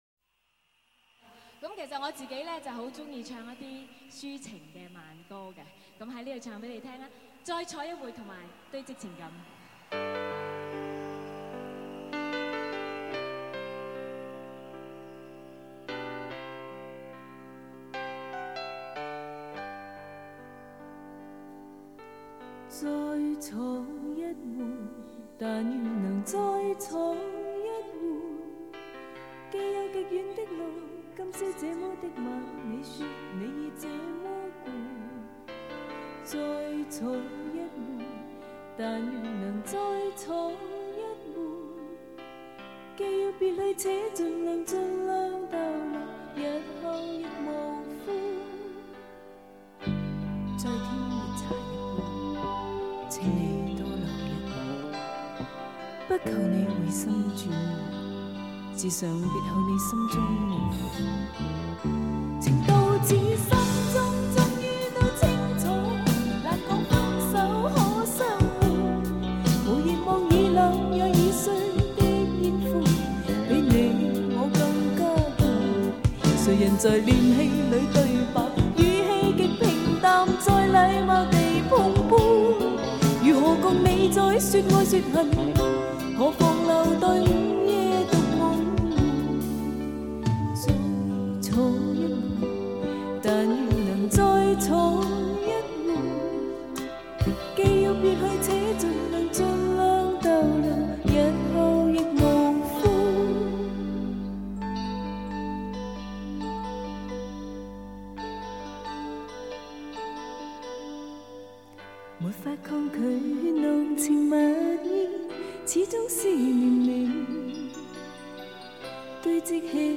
这是1987年，宝丽金十五周年演唱会，非常经典！